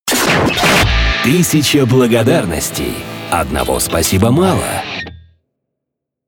джинглы